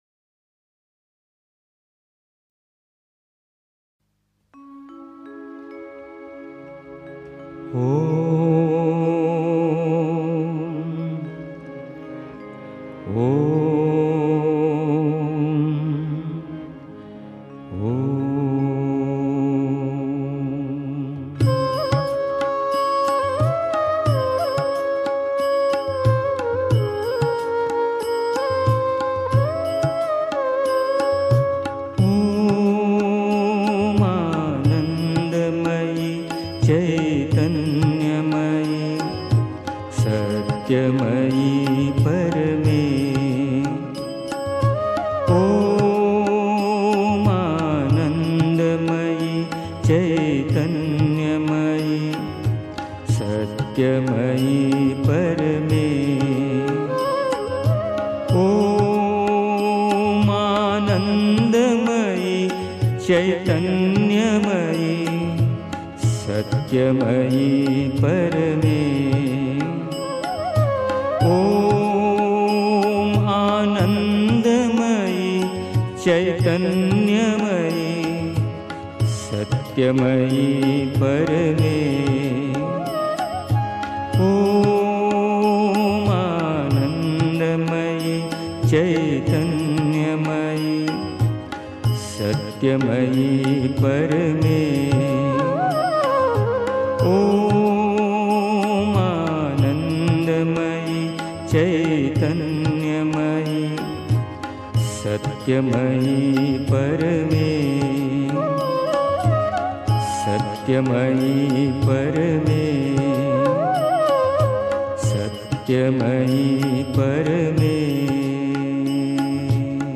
1. Einstimmung mit Musik. 2. Beginne von außen (Die Mutter, The Sunlit Path) 3. Zwölf Minuten Stille.